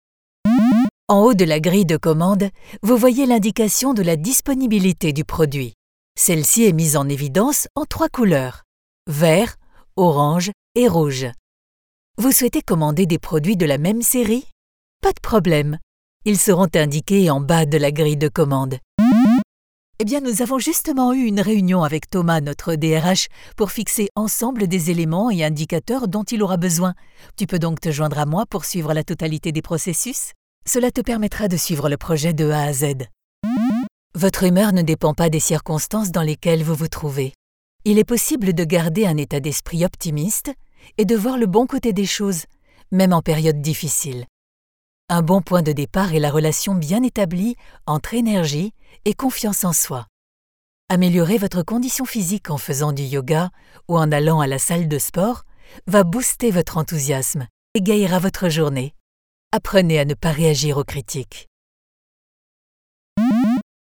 Französische Sprecherin. Flexible Stimme.
Sprechprobe: eLearning (Muttersprache):
French native Voice artist with professional Home Studio.